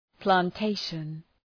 Προφορά
{plæn’teıʃən}